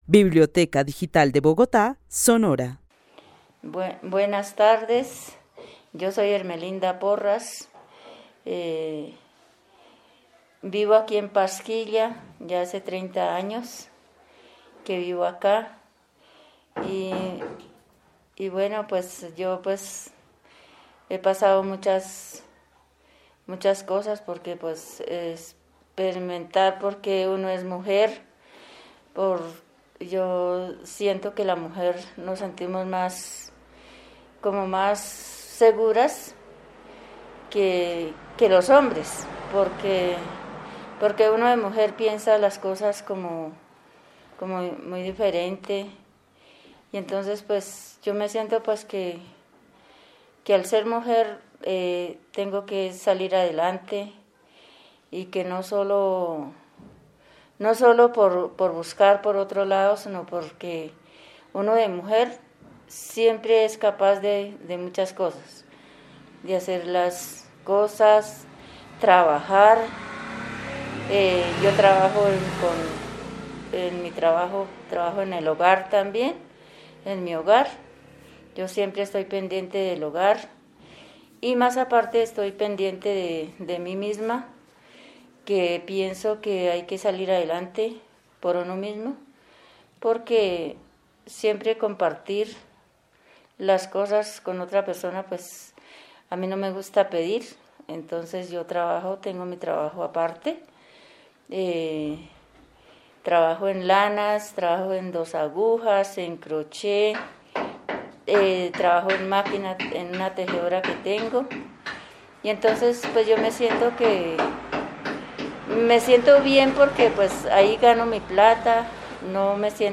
Narración oral de una mujer que vive en Pasquilla, zona rural de Bogotá. Desde su experiencia de vida considera que ser mujer es salir adelante, ser capaz de hacer muchas cosas y llegar a lo que se propone. También resalta que es importante no depender de nadie y aprender un arte.